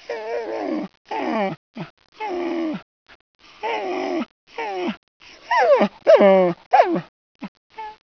begging.wav